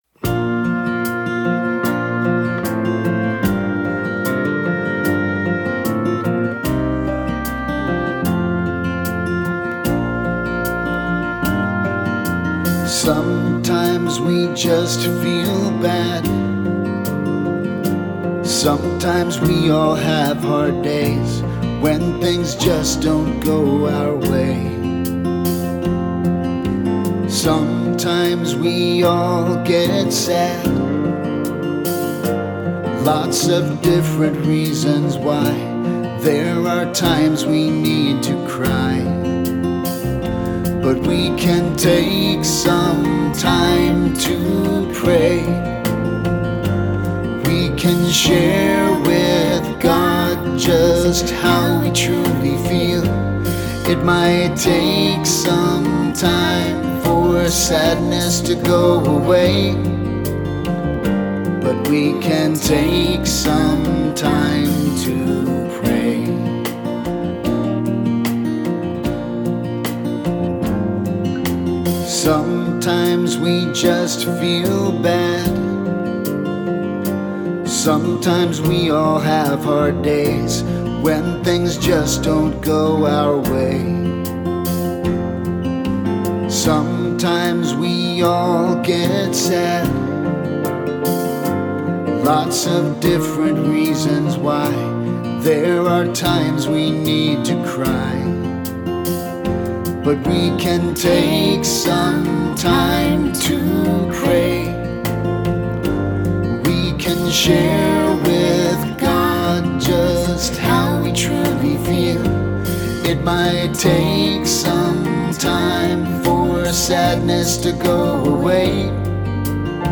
This children’s song is unusual in it’s message. It simply tells kids that it’s normal and natural to feel sad sometimes and to have difficult days, and that God understands all of our feelings.